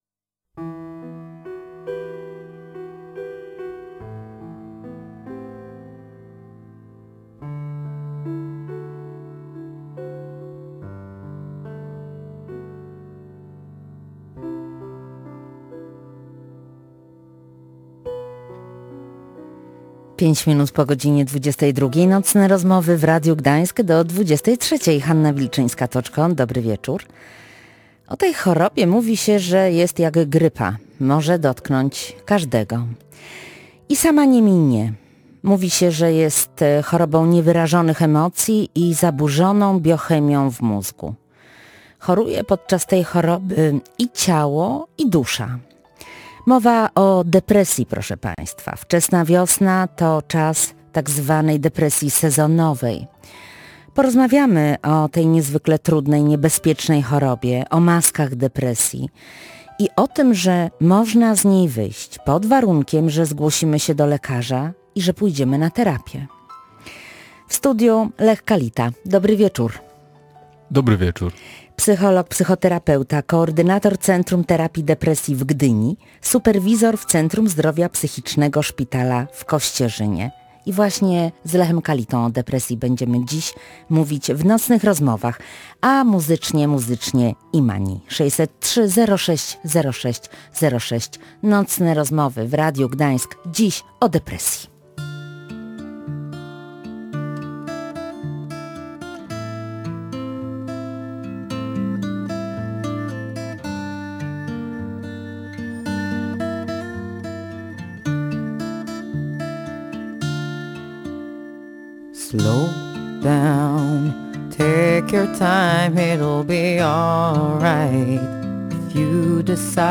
Psycholog tłumaczy, czym jest depresja i jak z nią walczyć
Specjalista mówi, jakie są objawy depresji, jak możemy pomóc bliskim, których dotknęła ta choroba, a także co robić, jeśli to my cierpimy na depresję.